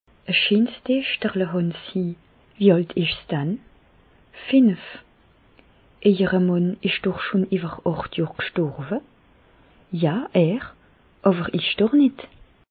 Bas Rhin
Reichshoffen